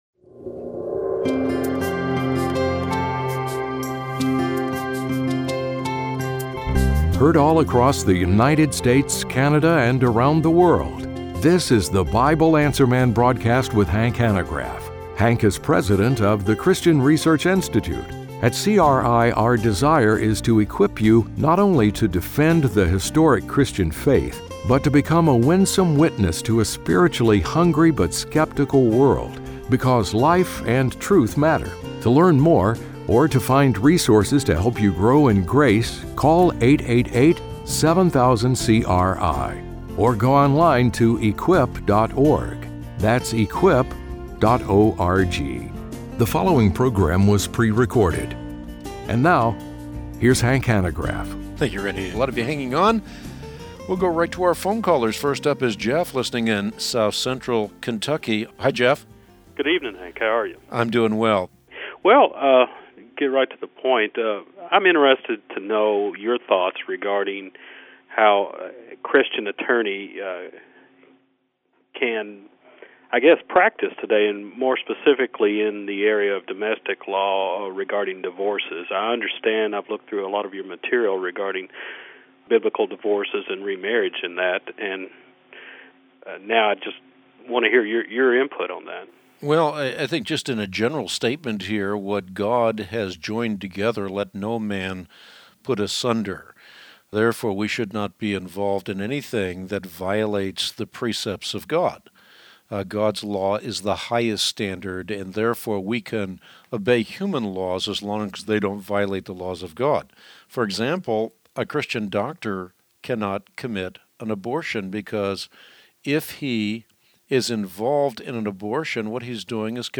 On today’s Bible Answer Man broadcast